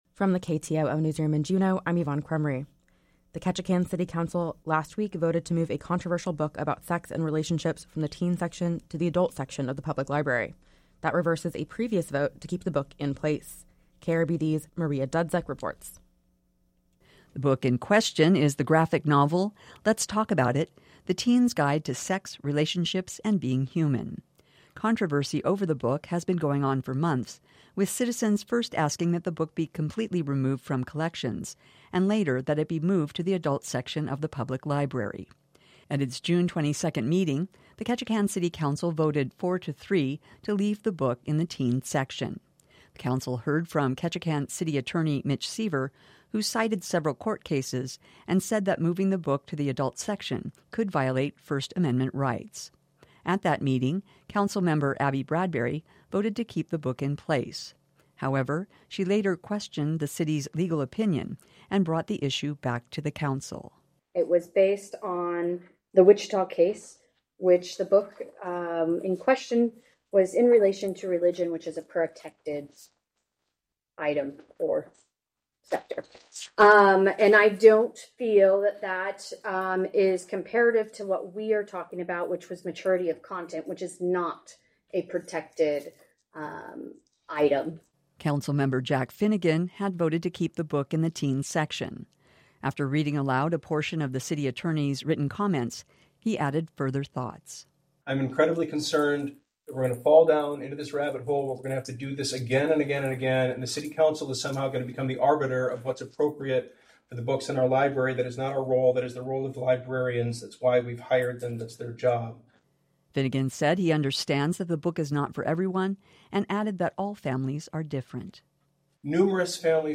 Newscast – Wednesday, July 12, 2023